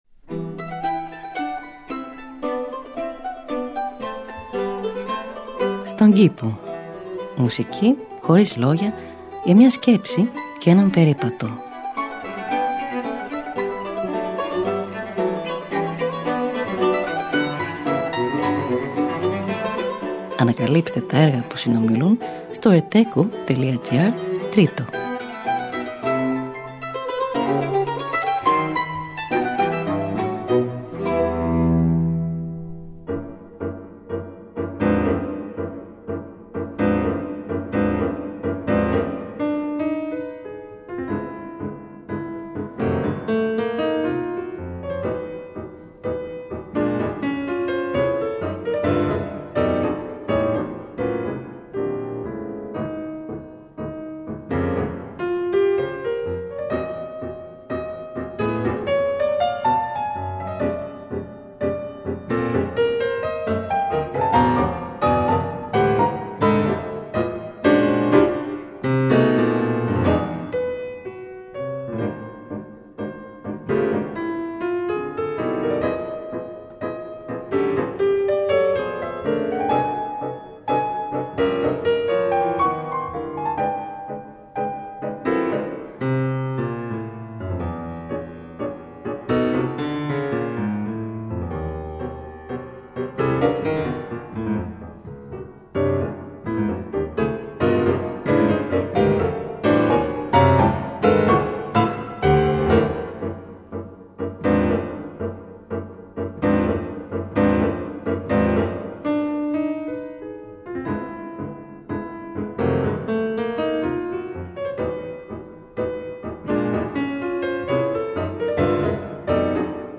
Μουσική Χωρίς Λόγια για μια Σκέψη και έναν Περίπατο.
Allegro – Arrange for mandolin and continuo: Avi Avital